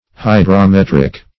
Hydrometric \Hy`dro*met"ric\, Hydrometrical \Hy`dro*met"ric*al\,